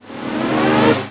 VEC3 Reverse FX
VEC3 FX Reverse 49.wav